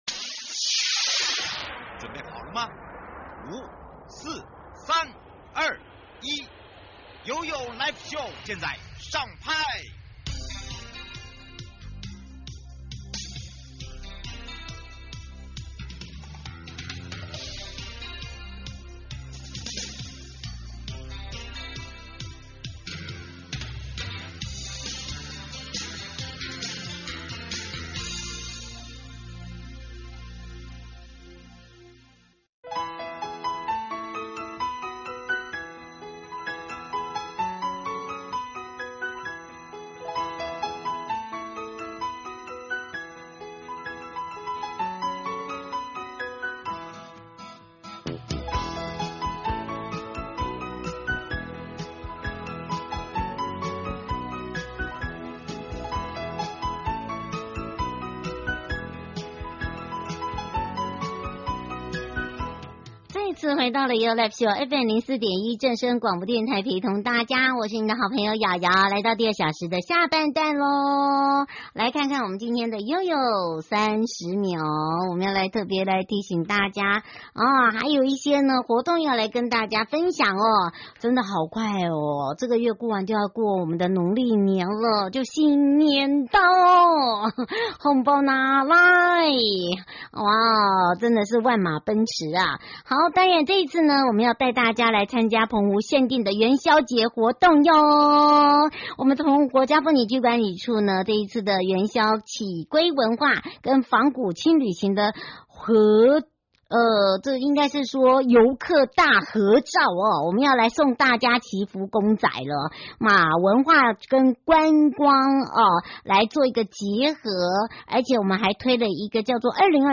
受訪者： 花東縱谷管理處許宗民處長